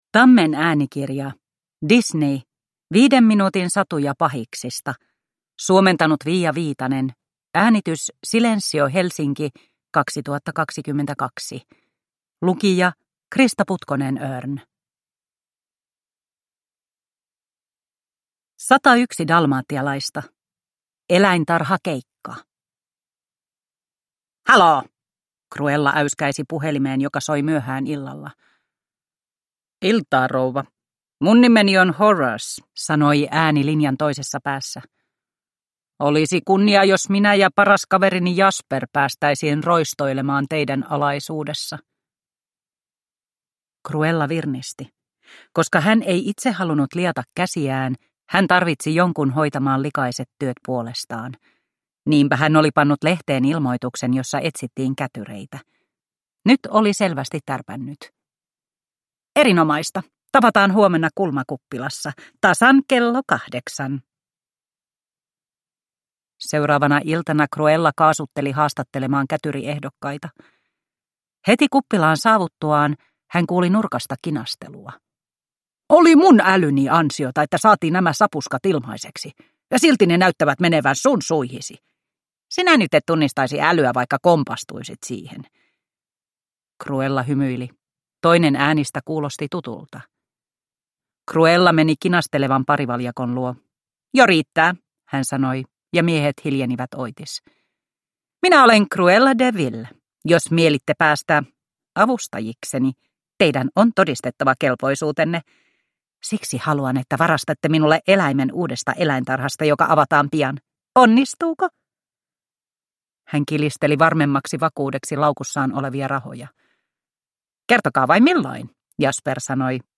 Disney. 5 minuutin satuja pahiksista – Ljudbok – Laddas ner